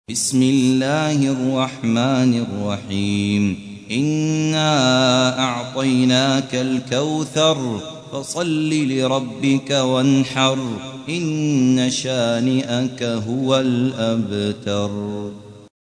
108. سورة الكوثر / القارئ